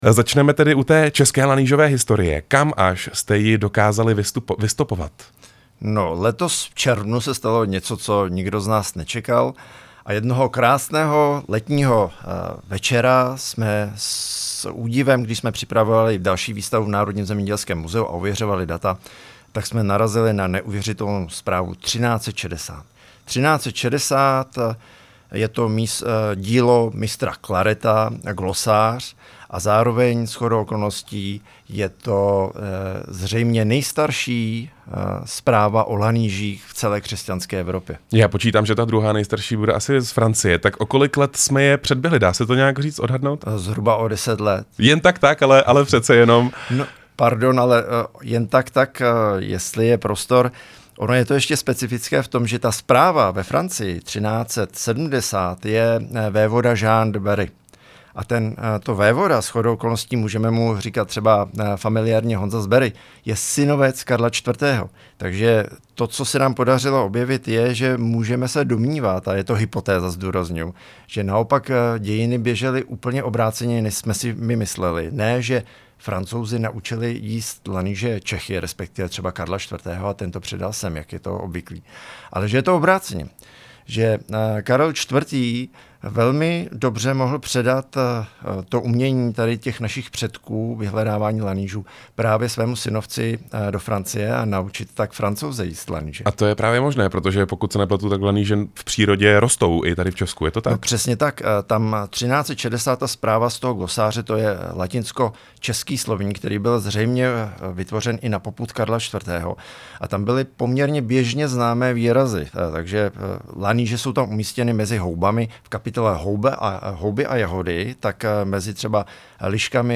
rozhovor rádia prostor